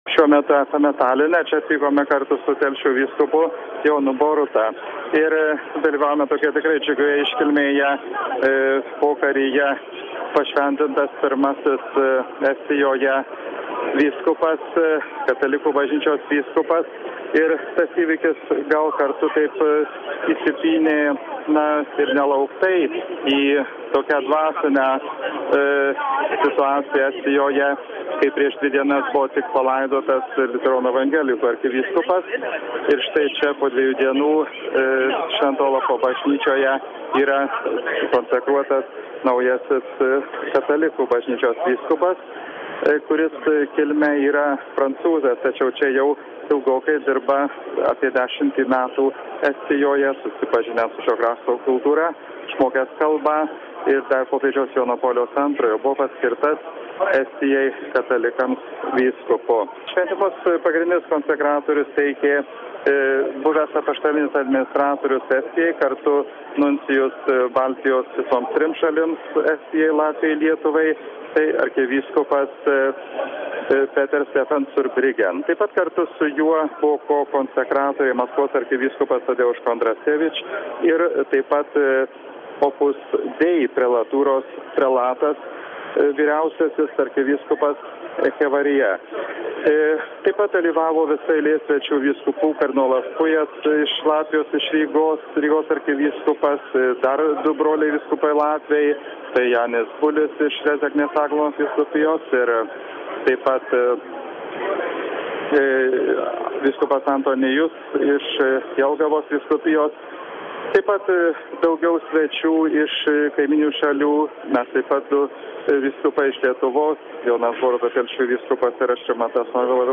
Šeštadienį kalbėjomės su naujo vyskupo šventimuose dalyvavusiu Vilkaviškio vyskupu Rimantu Norvila.